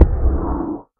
Minecraft Version Minecraft Version 25w18a Latest Release | Latest Snapshot 25w18a / assets / minecraft / sounds / mob / guardian / elder_hit2.ogg Compare With Compare With Latest Release | Latest Snapshot
elder_hit2.ogg